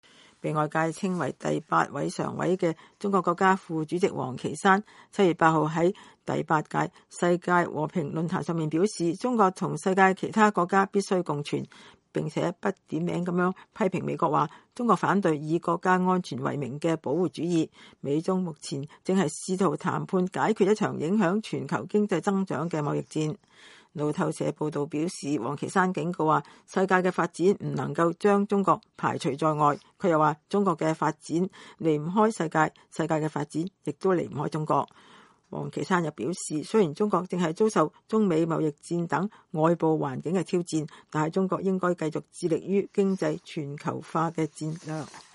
中國國家副主席王岐山2019年7月8日在北京清華大學舉行的第八屆世界和平論壇開幕式上講話。